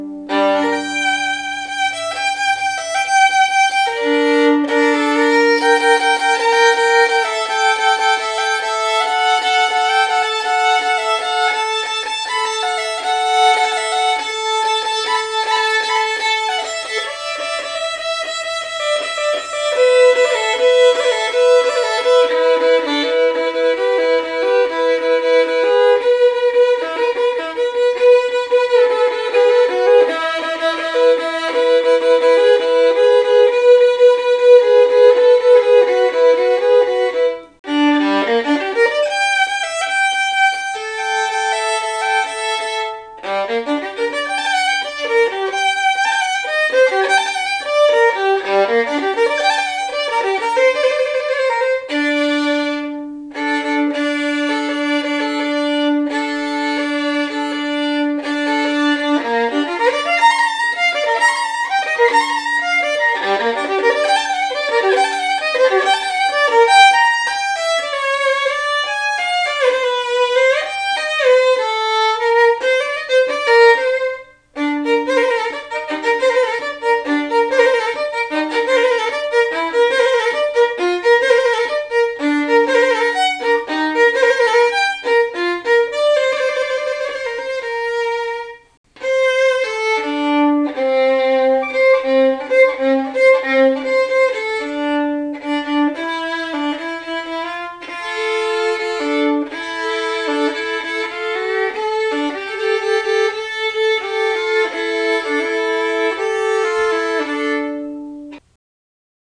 The acoustics here in our living room are exceptional, and really help to 'hear' accurately.
Click the four violins below: to hear real samples of "Rich" Tuned violins...